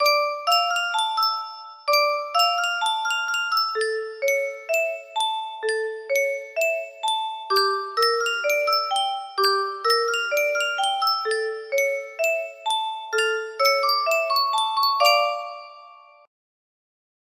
Yunsheng Music Box - Oihanian 6196 music box melody
Full range 60